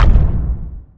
footstep3.wav